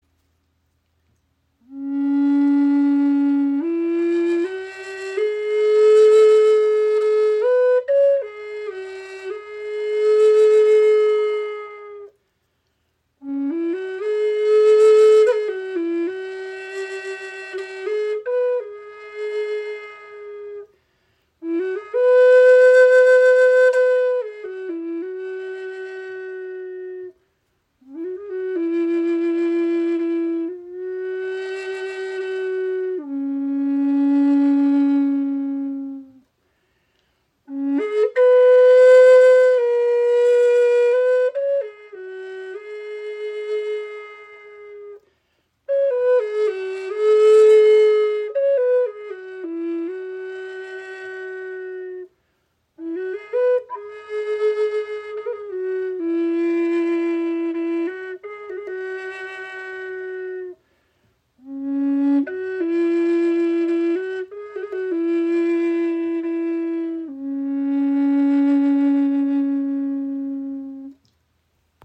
Gebetsflöte in D – 432 Hz | Der Geist des Phönix | Teakholz 69 cm
• Icon Gestimmt in D (432 Hz) mit tiefem, heilsamem Klang
Diese tiefe Gebetsflöte in D, gestimmt auf die harmonische Frequenz von 432 Hz, ist dem Spirit des Phönix gewidmet – dem Symbol für Transformation, Wiedergeburt und die unerschütterliche Kraft des Geistes.
So trägt jede Gebetsflöte ihre eigene Handschrift und einen unverwechselbaren, lebendigen Ton.